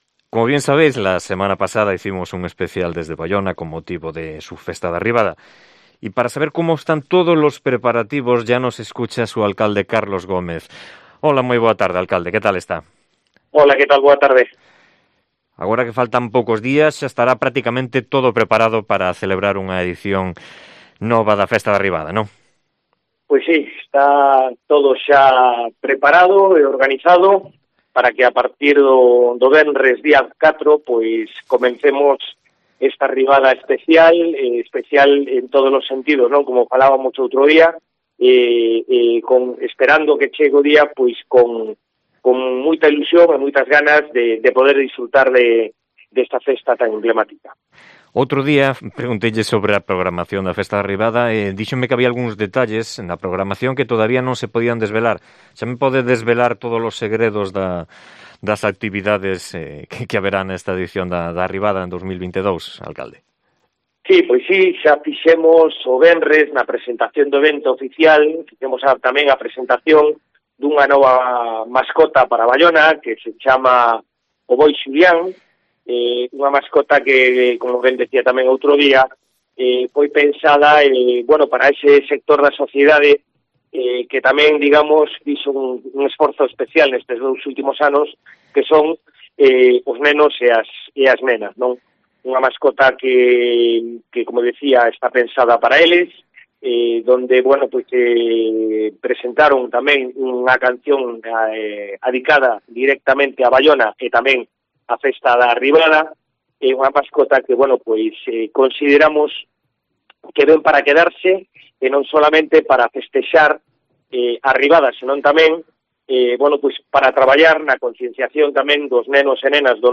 Entrevista con el alcalde de Baiona, Carlos Gómez, a pocos días del inicio de la Arribada